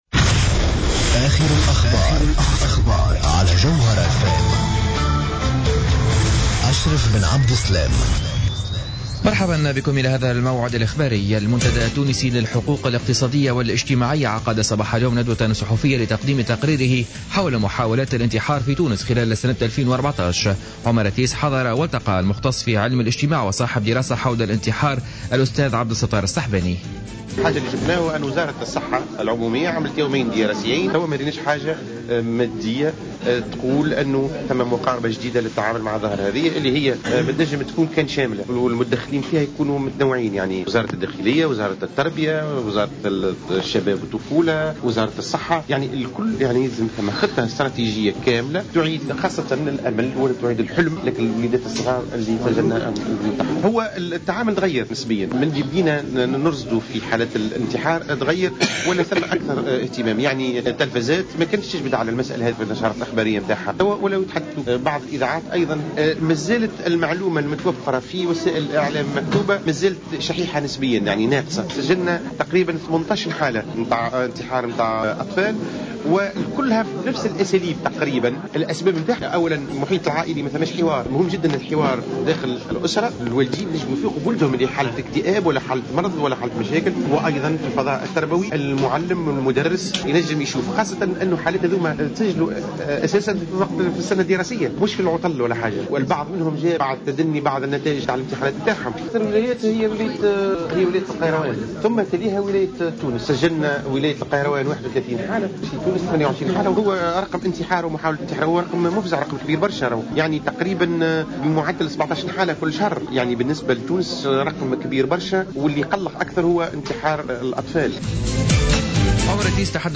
نشرة أخبار منتصف النهار ليوم الاربعاء 11 فيفري 2015